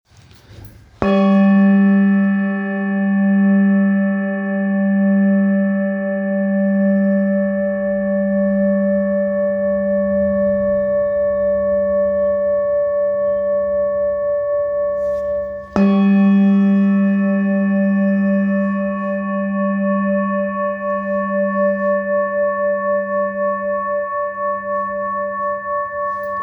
Singing Bowl, Buddhist Hand Beaten, with Fine Etching Carving, Ganesh, Select Accessories
Material Seven Bronze Metal